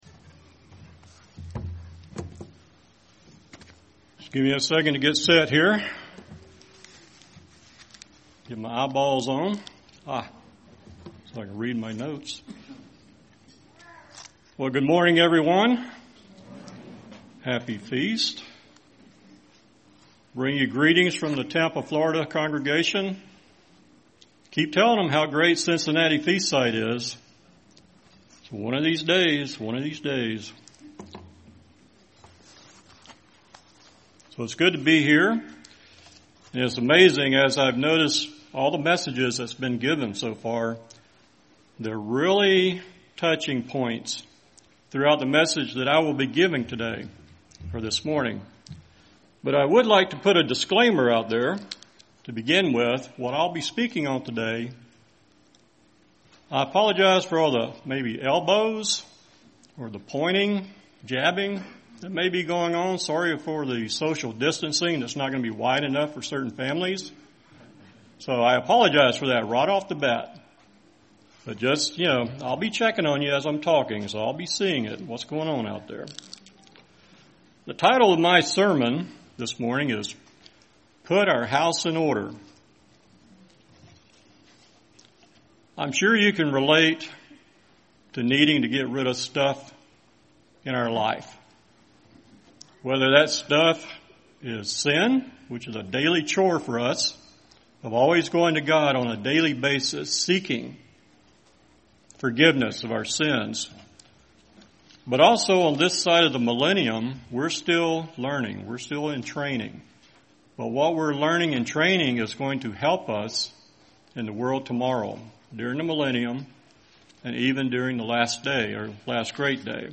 This sermon was given at the Cincinnati, Ohio 2020 Feast site.